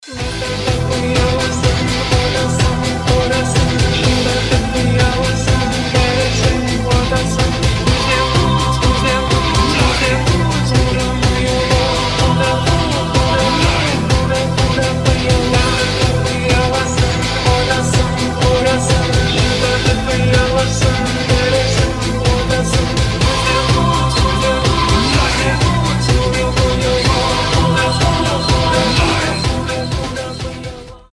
рок
метал